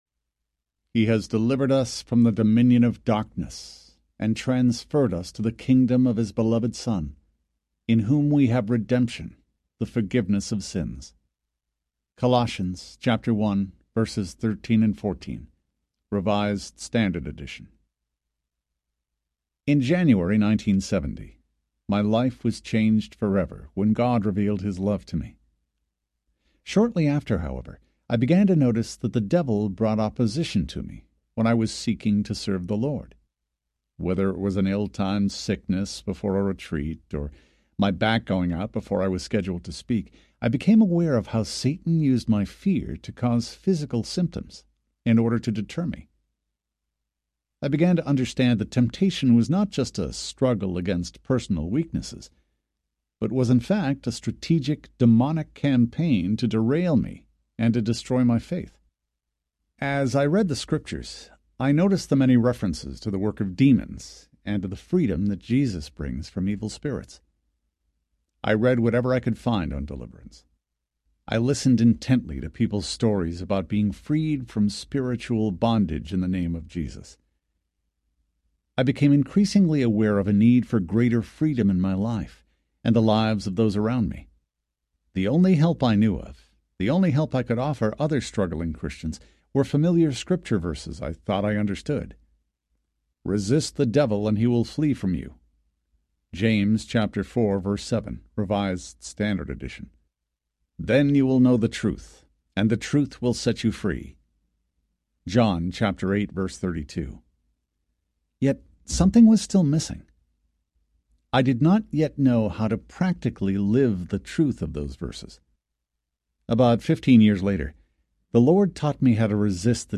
Unbound Audiobook